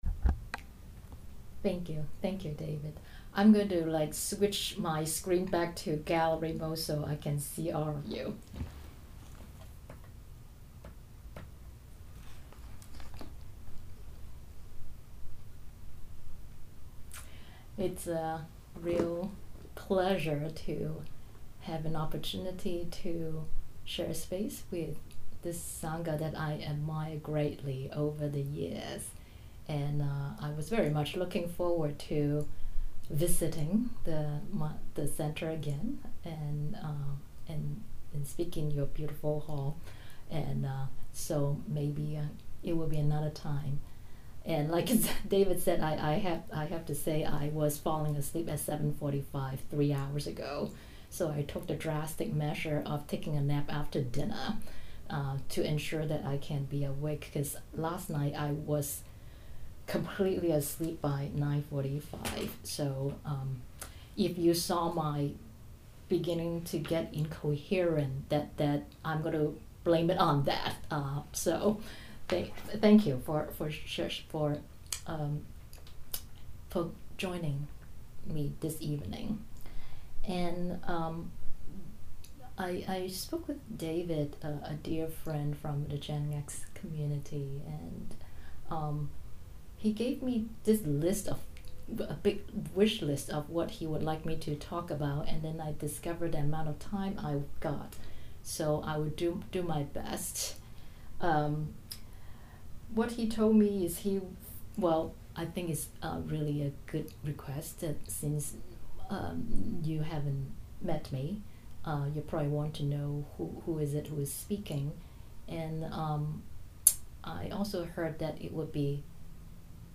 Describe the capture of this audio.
Because of the pandemic, she gave her talk to the sangha via Zoom instead.